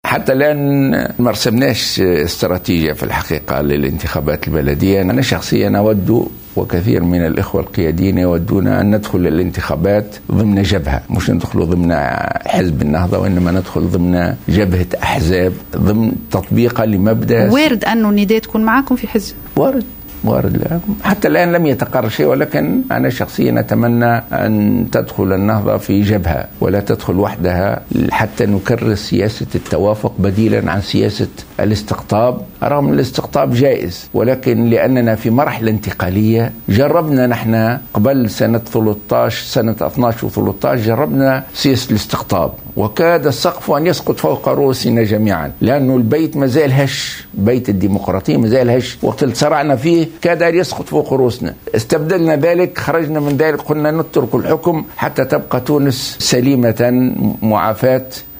وقال الغنوشي في حوار أجراه مع القناة الوطنية، بث الجمعة، أن النهضة لم تضع بعد استراتيجيتها لخوض هذا الاستحقاق الانتخابي إلا أن عددا من قيادي الحزب يؤيدون دخوله ضمن جبهة أحزاب موحدة، قد يكون النداء من بين مكوناتها.